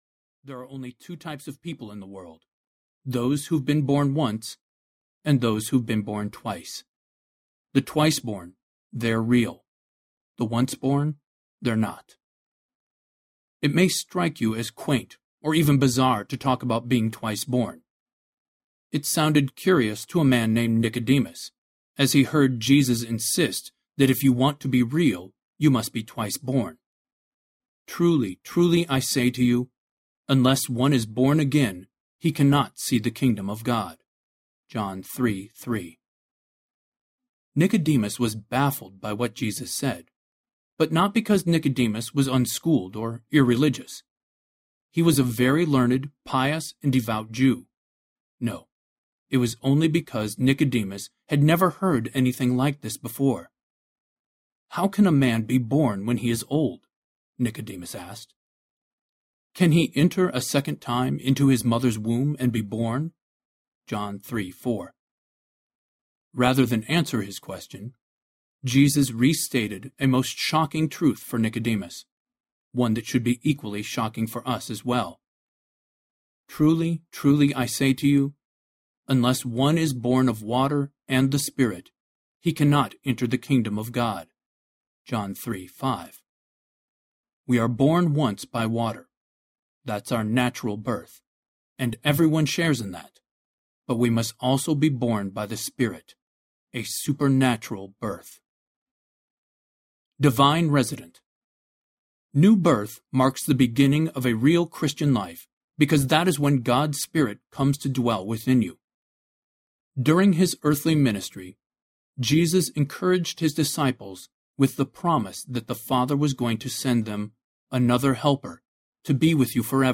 Real Christian Audiobook
Narrator
4.5 Hrs. – Unabridged